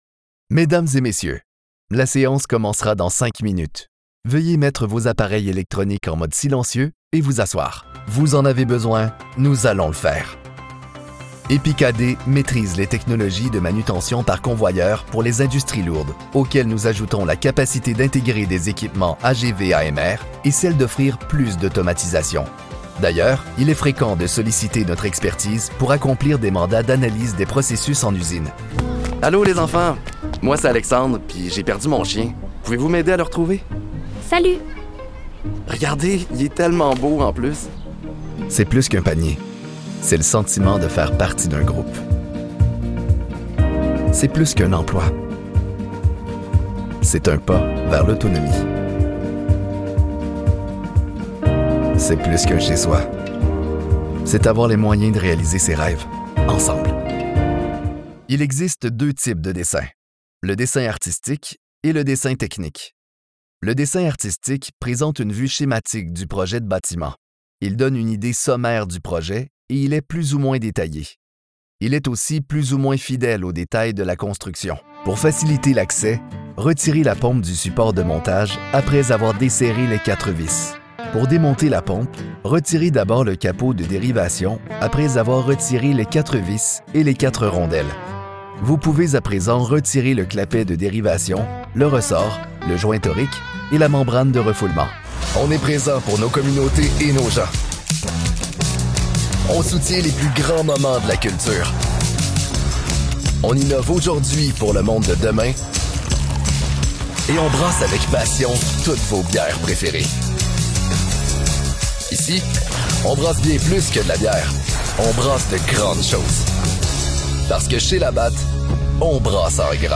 Corporate & Industrial Voice Overs
Yng Adult (18-29) | Adult (30-50)